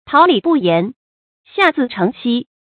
táo lǐ bù yán，xià zì chéng xī
桃李不言，下自成蹊发音